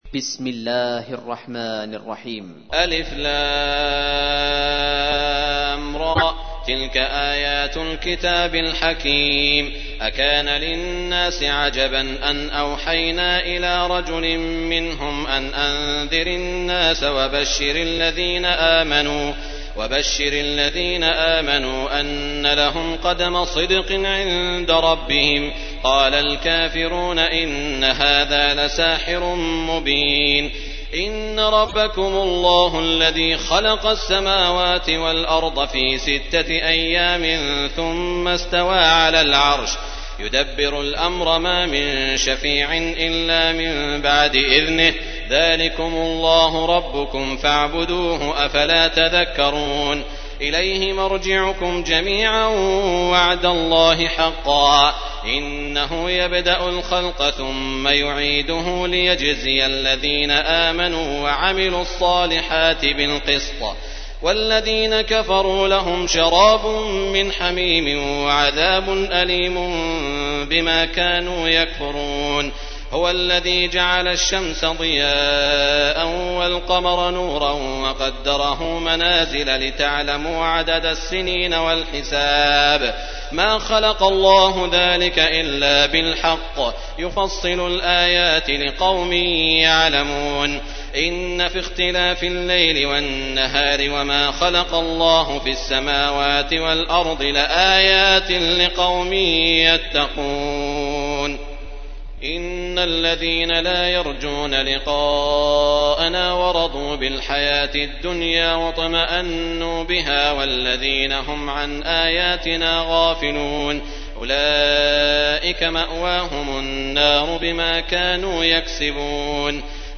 تحميل : 10. سورة يونس / القارئ سعود الشريم / القرآن الكريم / موقع يا حسين